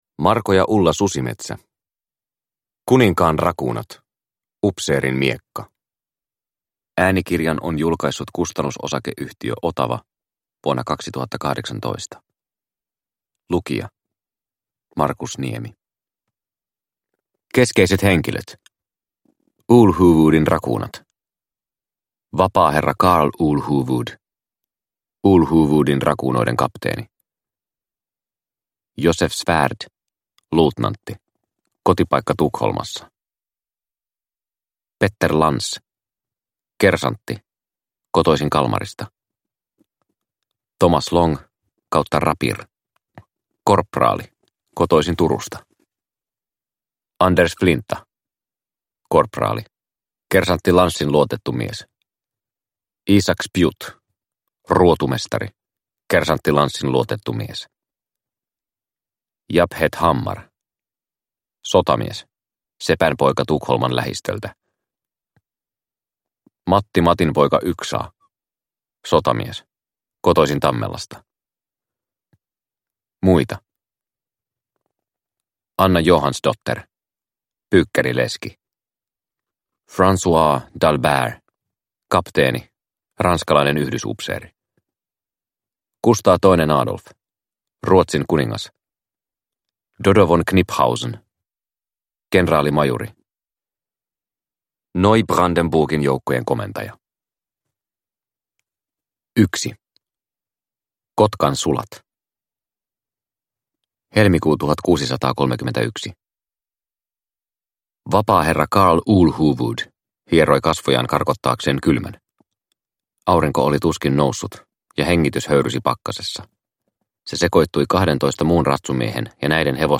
Kuninkaan rakuunat - Upseerin miekka – Ljudbok – Laddas ner